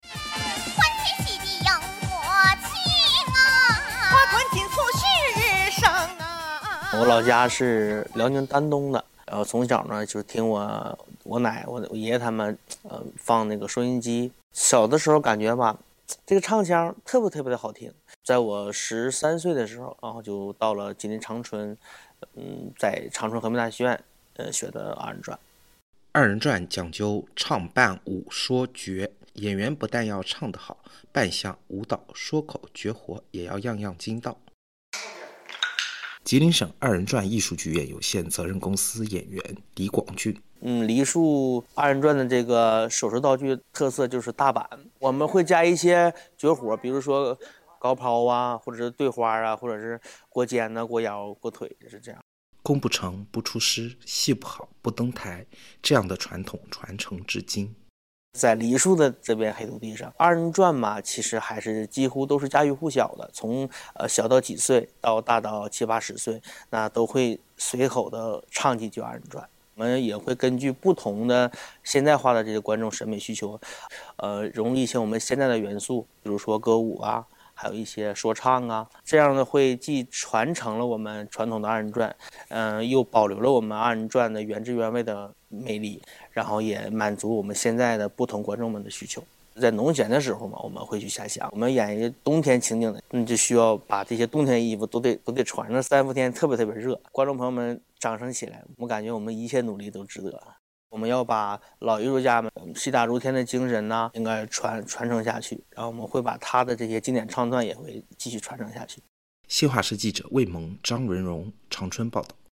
二人转讲究“唱扮舞说绝”。演员不但要唱得好，扮相、舞蹈、说口、绝活也要样样精到。